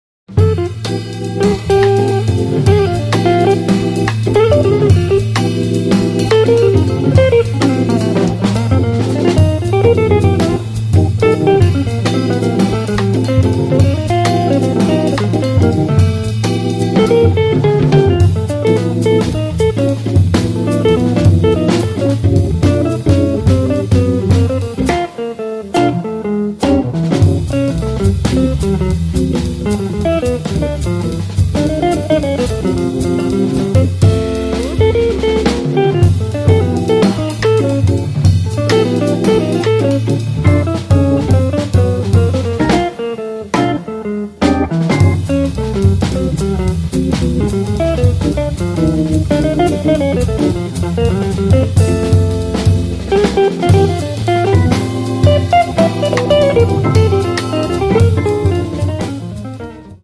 guitar
hammond B3 Organ
drums